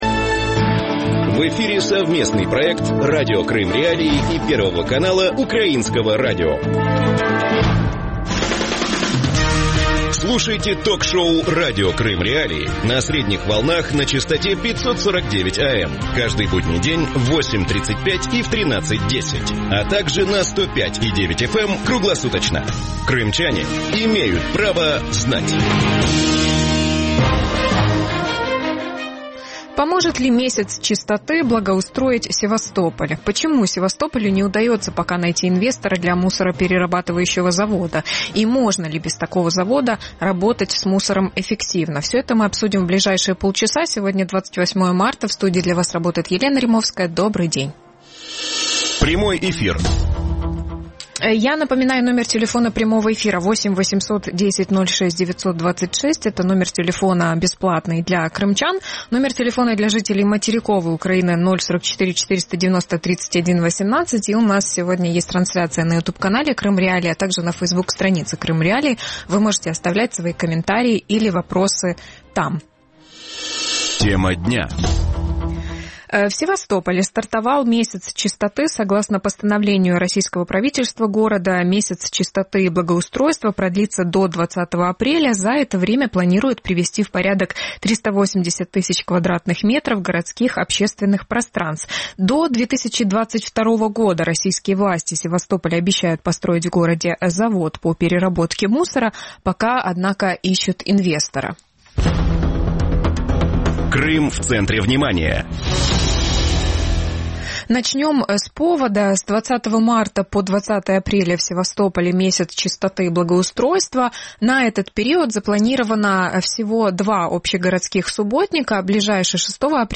Сколько «месяцев чистоты» нужно для благоустройства Севастополя? Когда в Севастополе появится мусороперерабатывающий завод? Можно ли без такого завода работать с мусором эффективно? Гости эфира
крымский эколог